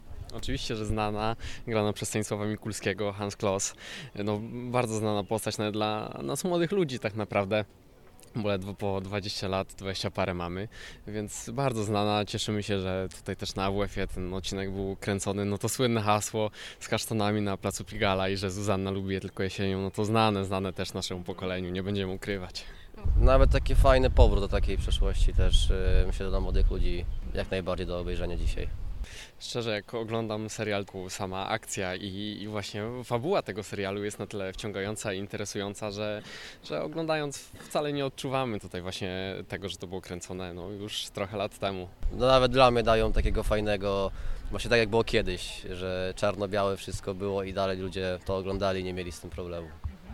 Sprawdziliśmy co studenci wiedzą o serialu i Hansie Klossie.